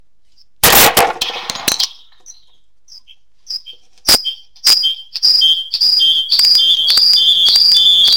2 cellulari, speaker bluetooth, martello.
Performance, 2020.
producono l’effetto Larsen.
amplifica l’effetto Larsen.
ad ogni ripetizione in loop.